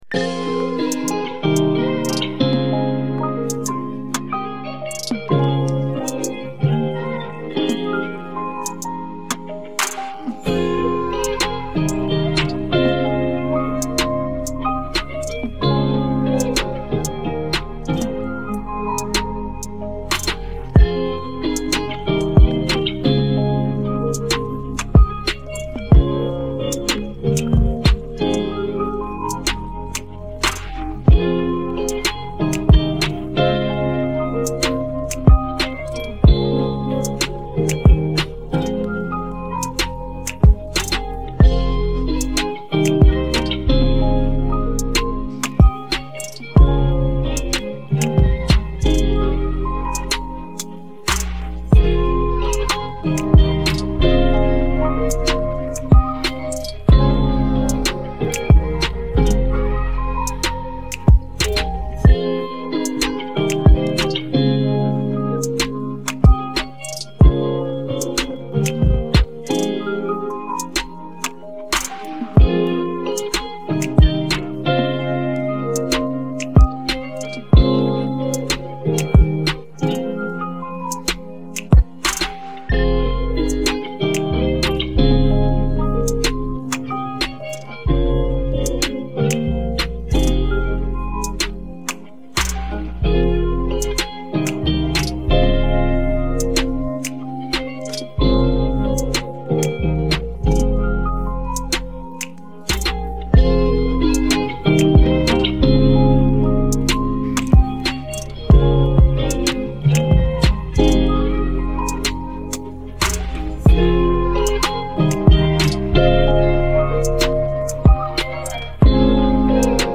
Baggrundsmusik